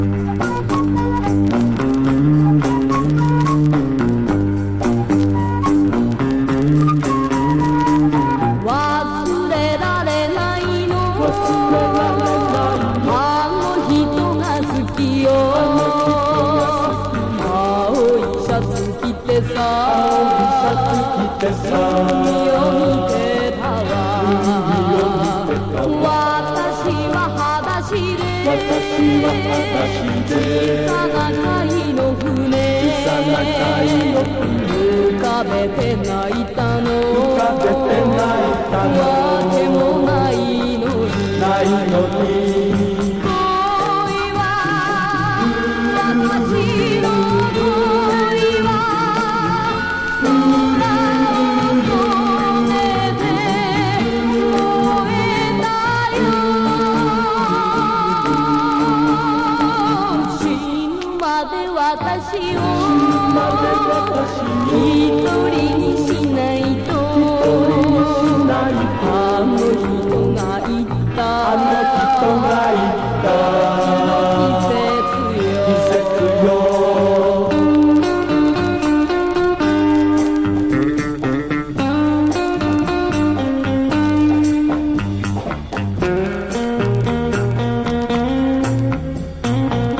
60-80’S ROCK
(チリノイズあり)